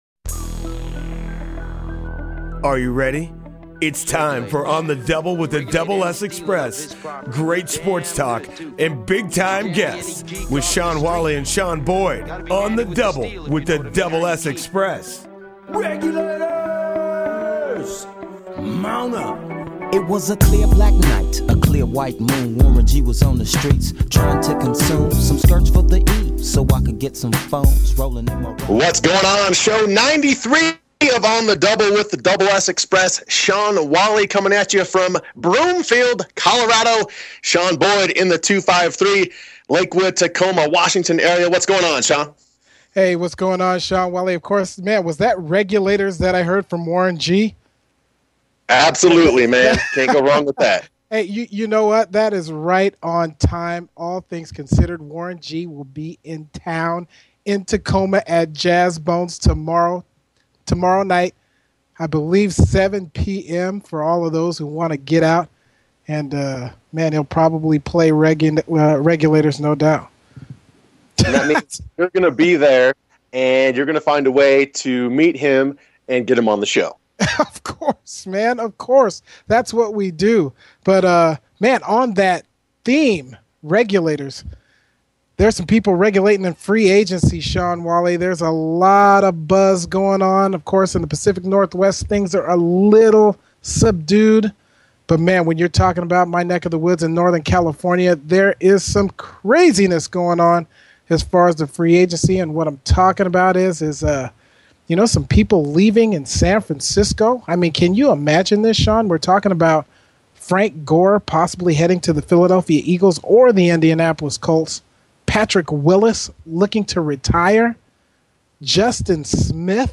On the Double with The Double S Xpress sports talk show; Sports Broadcasting; Play-by-Play; Analysis; Commentary; Insight; Interviews; Public Address Mission: To provide the best play-by-play & analysis of all sports in the broadcasting world.